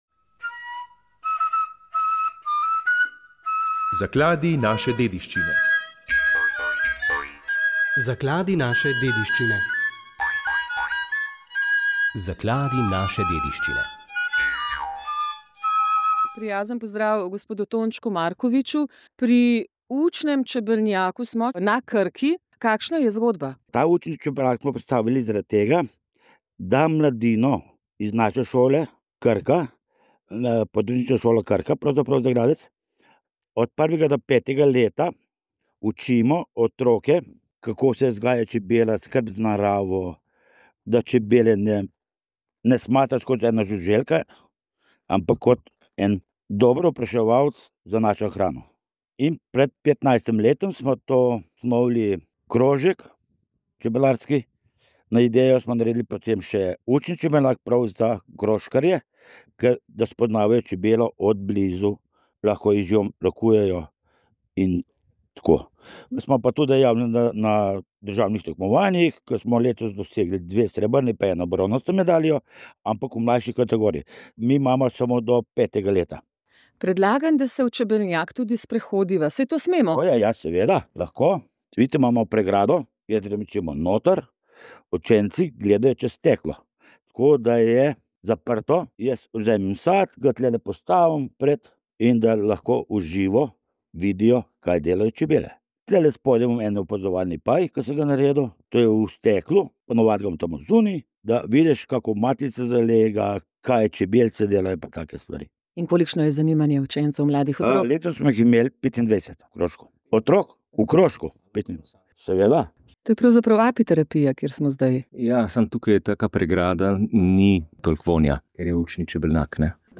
Ob Svetovnem dnevu Zemlje, ki ga obeležujemo dvaindvajsetega aprila, smo k pogovoru za poljudnejšo razlago podnebnih sprememb povabili vse bolj prepoznavnega in priznanega meteorologa, ki pri svojem profesionalnem delu združuje med drugimi tudi znanja fizike, matematike, tudi kemije, v prepletu z meteorologijo.